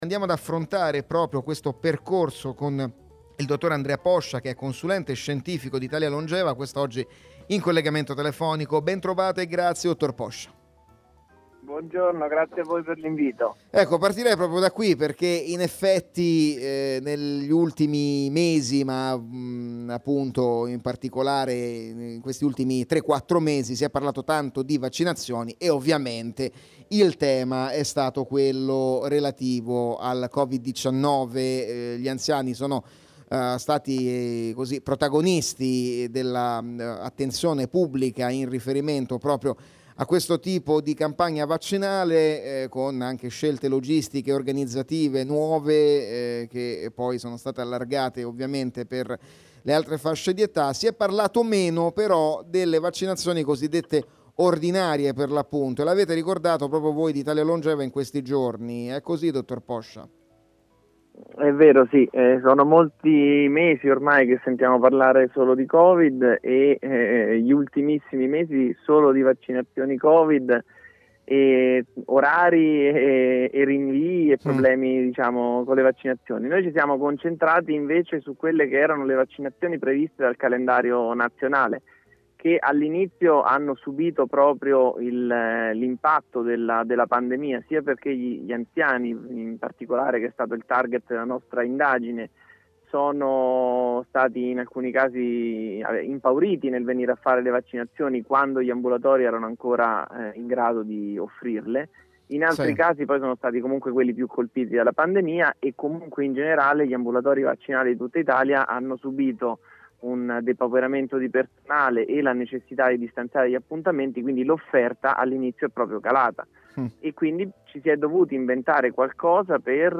Radio Cusano Campus, Genetica Oggi – intervista